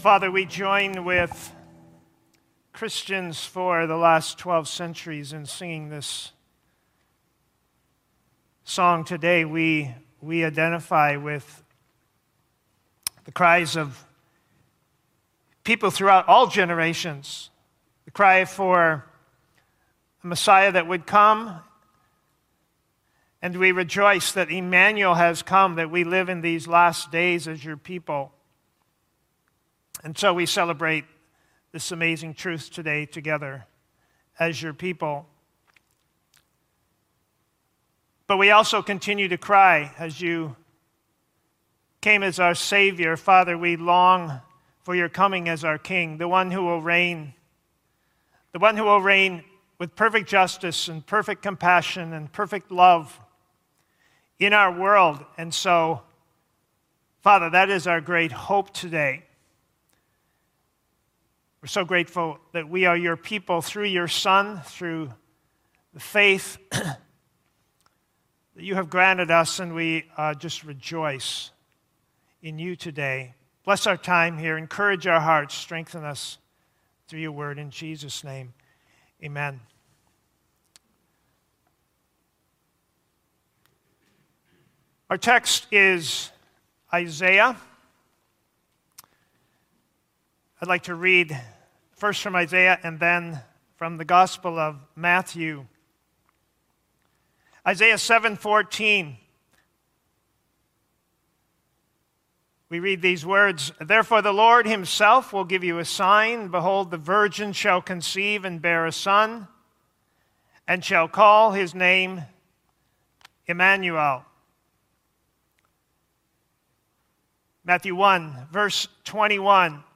First-Baptist-Sermon-December-13-2020.mp3